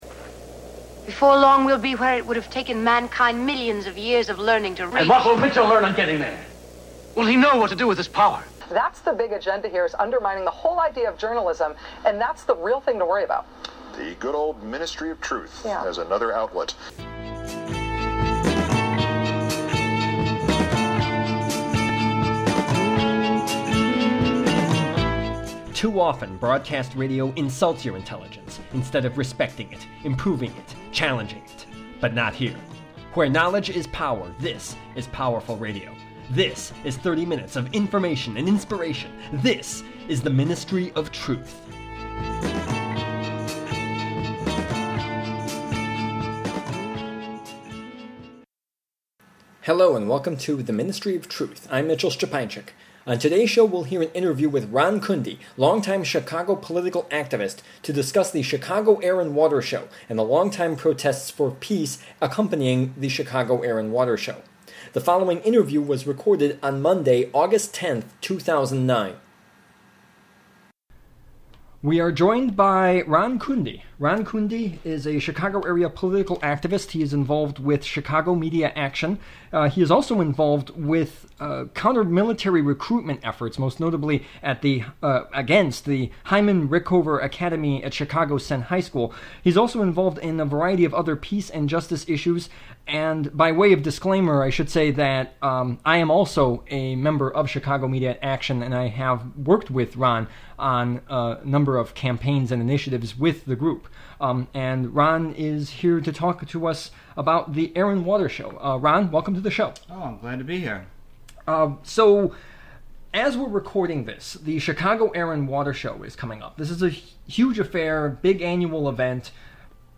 The Ministry of Truth: Interview
radio